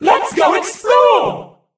bea_mon_start_vo_01.ogg